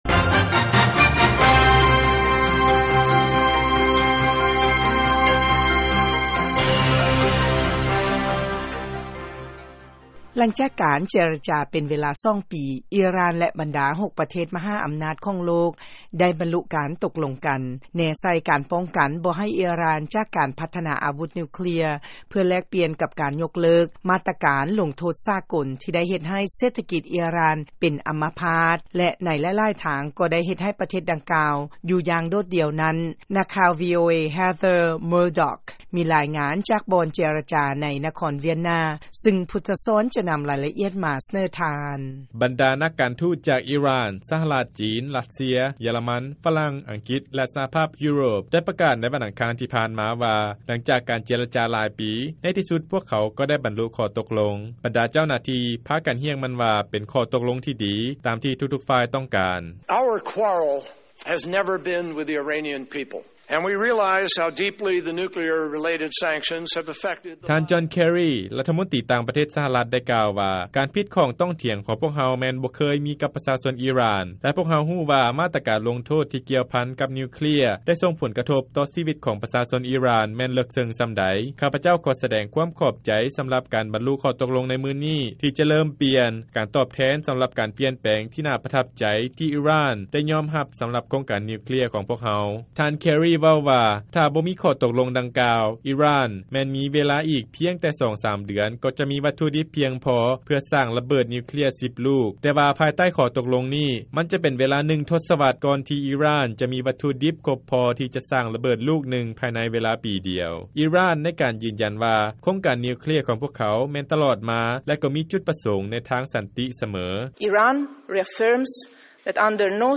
ຟັງລາຍງານ ອິຣ່ານ ແລະ ບັນດາປະເທດ ມະຫາອຳນາດໂລກ ບັນລຸຂໍ້ຕົກລົງ ໂຄງການນິວເຄລຍ